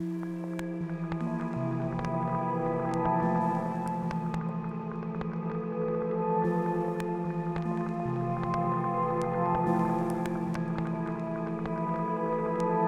Navy_Piano.wav